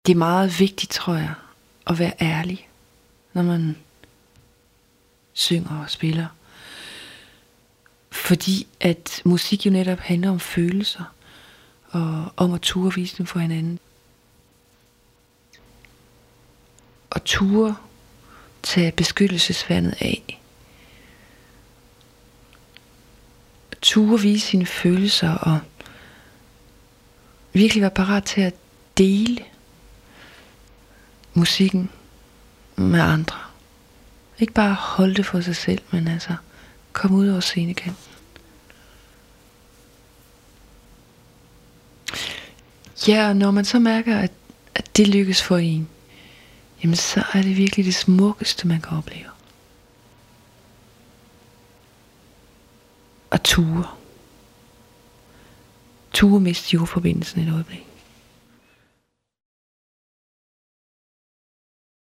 Soundbites for use in radio
• Download interview sound bites, raw, without music: